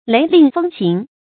雷令风行 léi lìng fēng xíng 成语解释 犹言雷厉风行。
ㄌㄟˊ ㄌㄧㄥˋ ㄈㄥ ㄒㄧㄥˊ